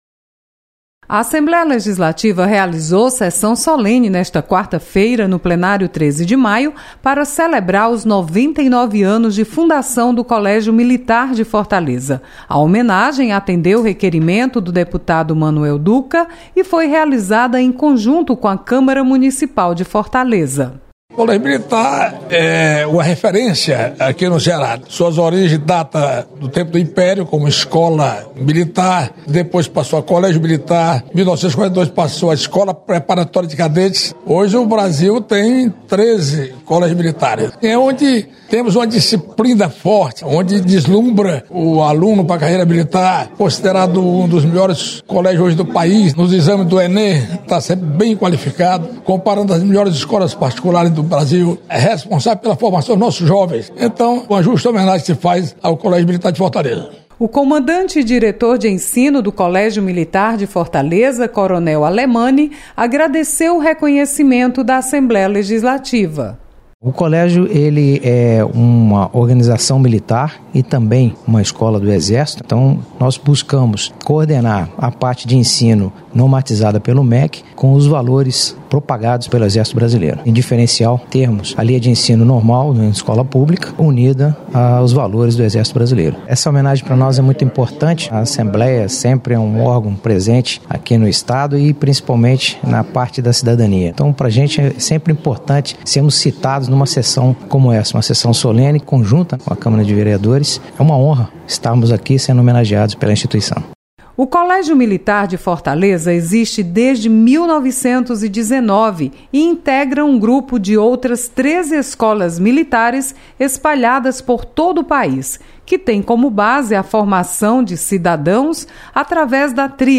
Você está aqui: Início Comunicação Rádio FM Assembleia Notícias Solenidade